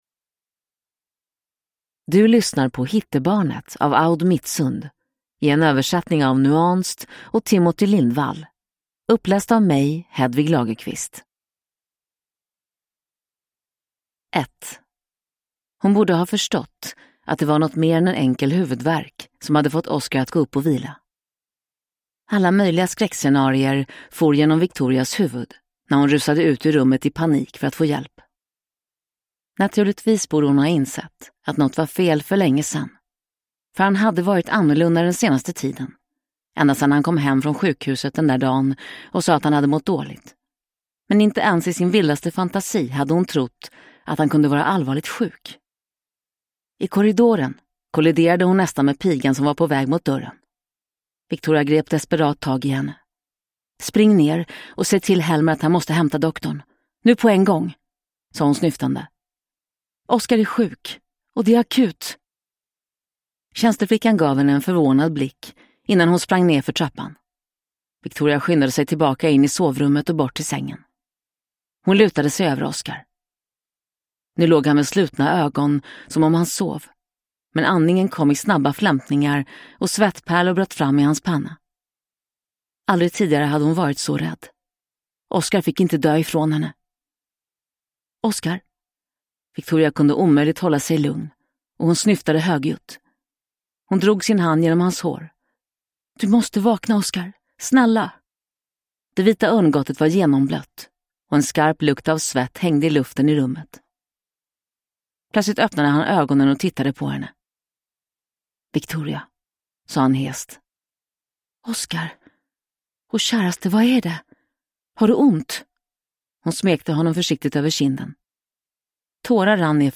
Hittebarnet – Ljudbok